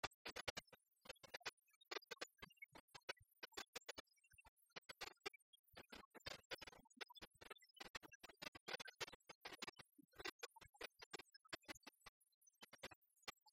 Château-d'Olonne (Le)
danse : branle : courante, maraîchine ;
Catégorie Pièce musicale inédite